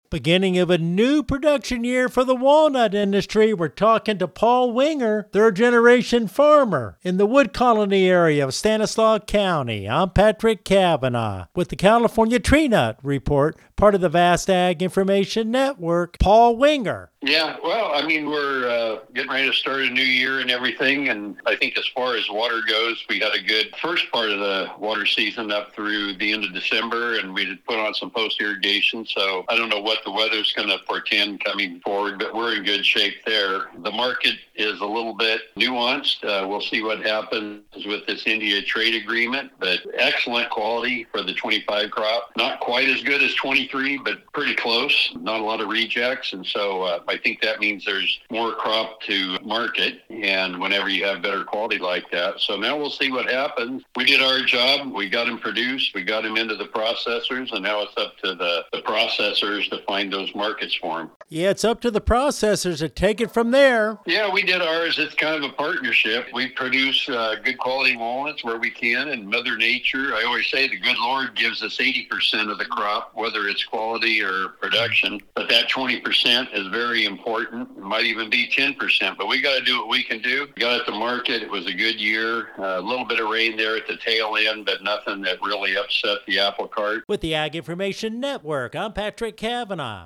Walnut Grower On the New Crop Year